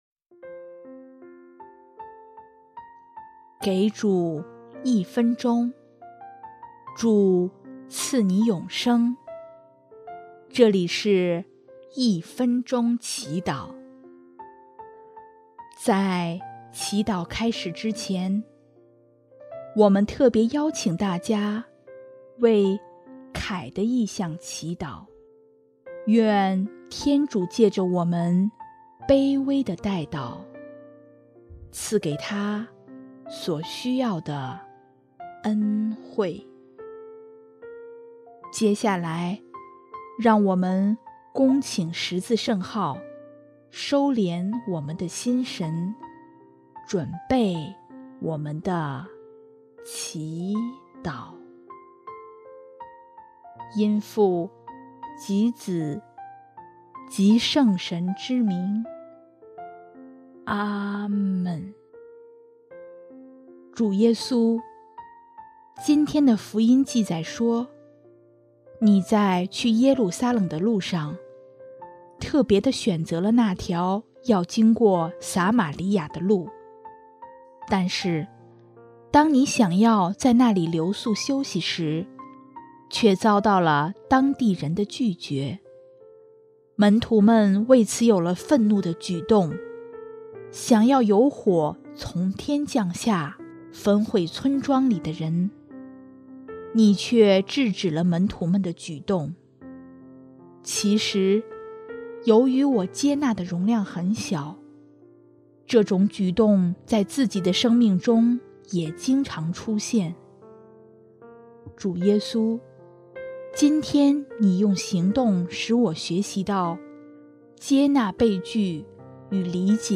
【一分钟祈祷】|10月3日 接纳与包容，善用自己的能力